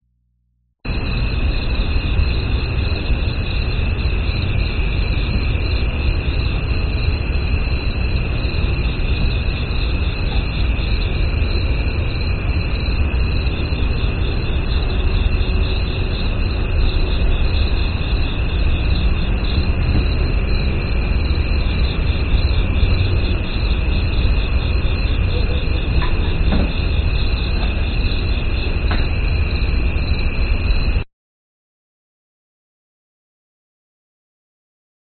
蟋蟀
描述：郊区后院蟋蟀的双耳（立体声环绕声）录音
Tag: 氛围 环境